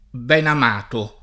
benamato
benamato [ benam # to ]